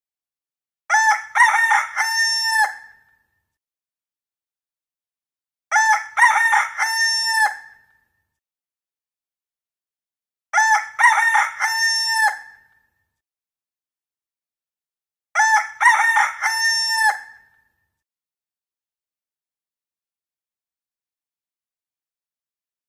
دانلود آهنگ خروس 1 از افکت صوتی انسان و موجودات زنده
دانلود صدای خروس 1 از ساعد نیوز با لینک مستقیم و کیفیت بالا
جلوه های صوتی